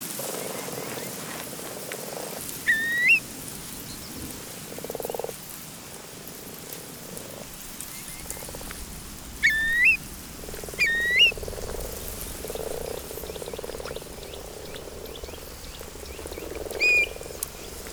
Luister naar het moeras peatland sounds I'm a crane—long legs, long journeys, and a soft spot for wet feet.
peatland-soundscape-17s.mp3